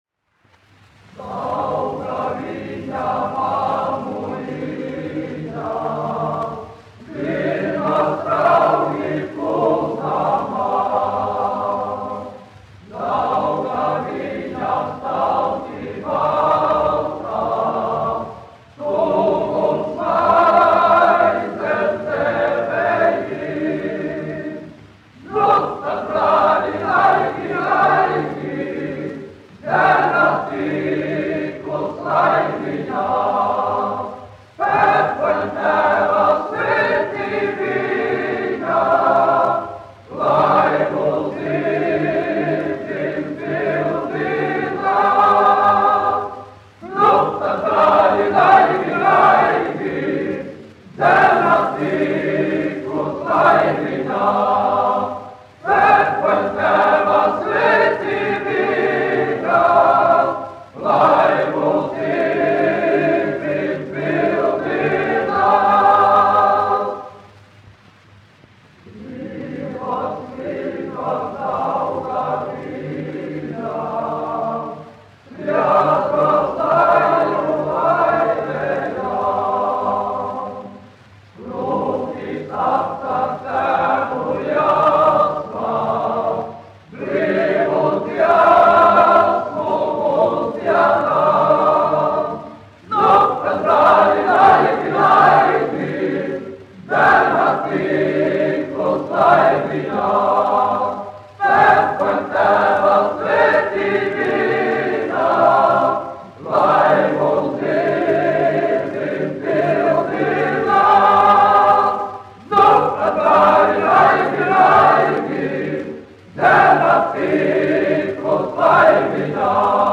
Prezidiju konventa vīru koris, izpildītājs
Kalniņš, Teodors, 1890-1962, diriģents
1 skpl. : analogs, 78 apgr/min, mono ; 25 cm
Kori (vīru)
Latvijas vēsturiskie šellaka skaņuplašu ieraksti (Kolekcija)